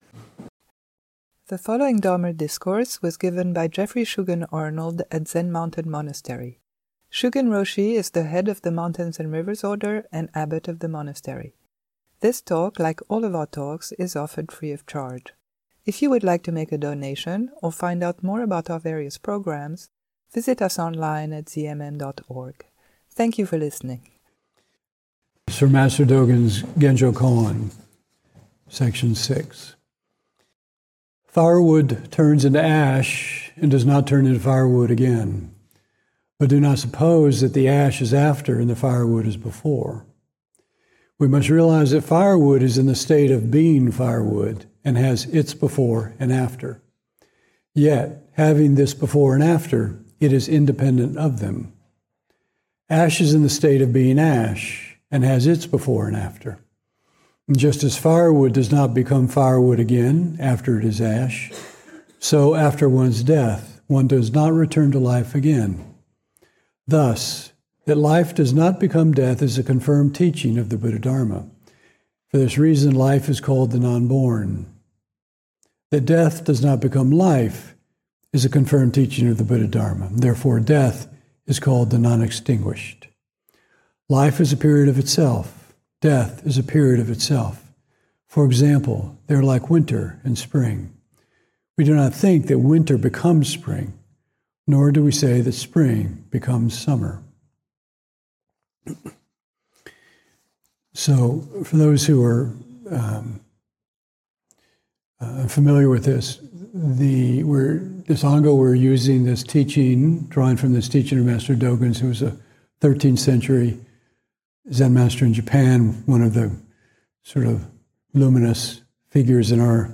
Rather than living in memory and recollection, or in our hopes and fears, Dogen’s Genjokoan emphasizes that the dharma state of any phenomenon is just this, right now. - Fall 2025 Ango - Genjokoan Series of Talks - Part 6 See all episodes